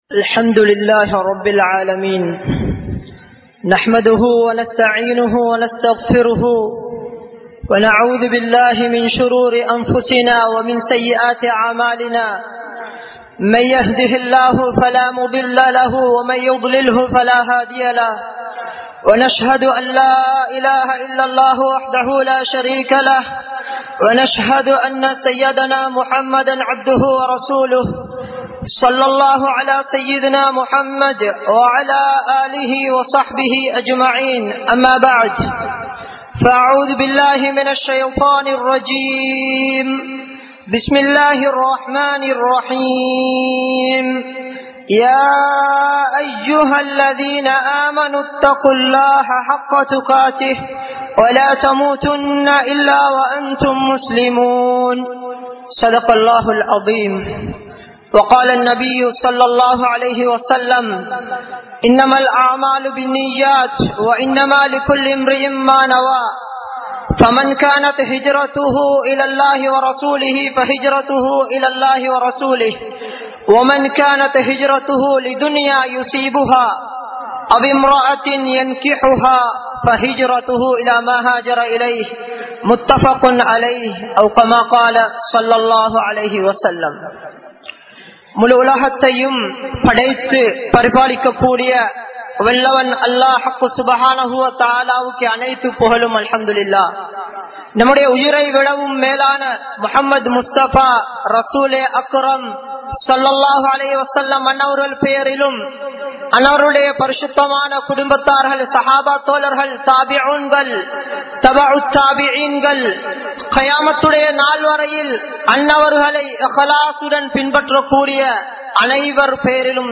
Indraya Veeduhalum Nabi Valium (இன்றைய வீடுகளும் நபி வழியும்) | Audio Bayans | All Ceylon Muslim Youth Community | Addalaichenai
Jumua Masjidh